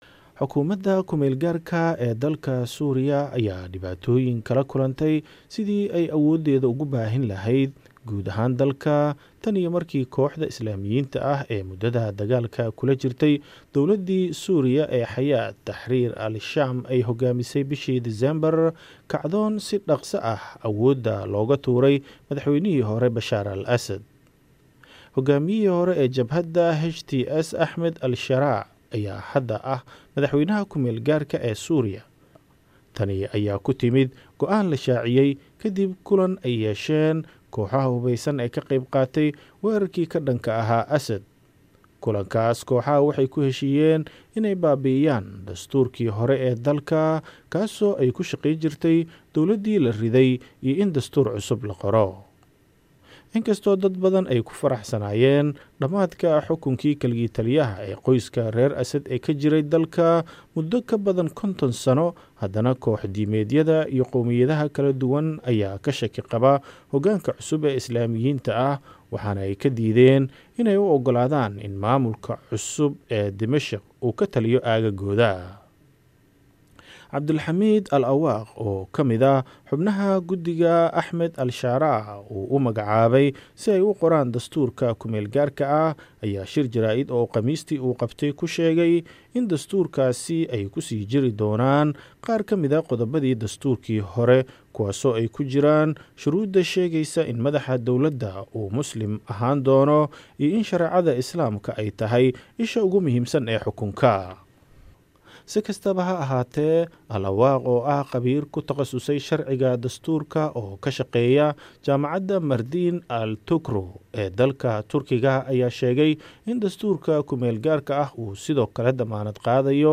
Warbixin ay arrintan ka qortay VOA